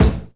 JAZZ KICK 5.wav